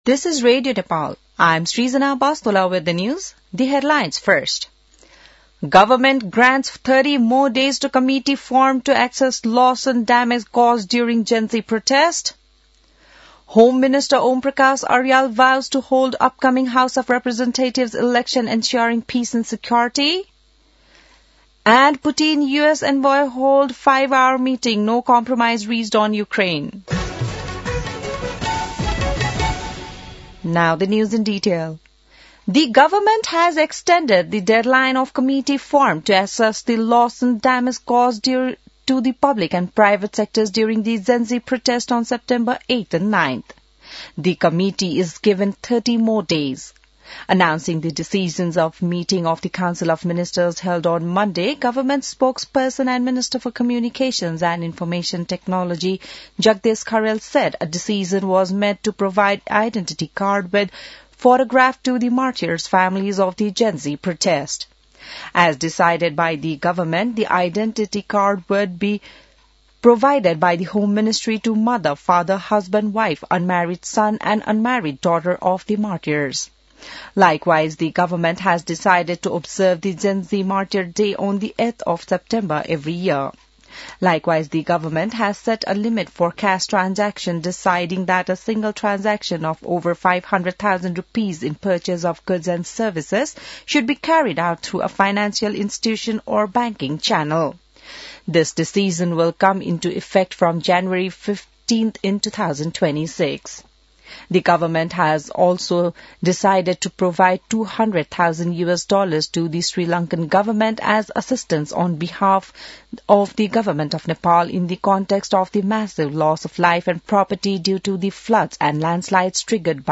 बिहान ८ बजेको अङ्ग्रेजी समाचार : १७ मंसिर , २०८२